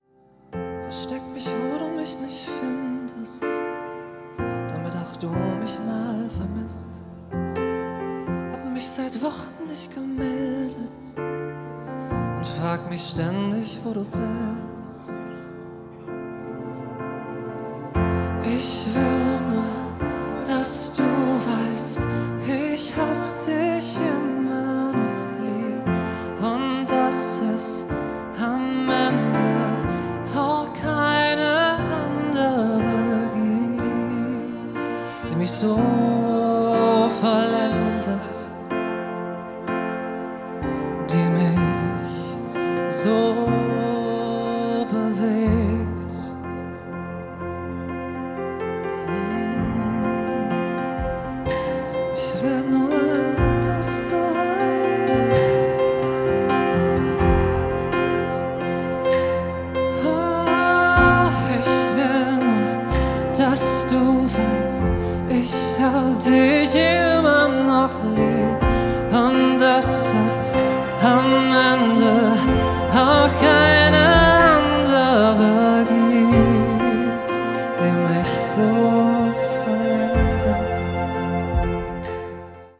live von der celebrations Hochzeitsmesse Frankfurt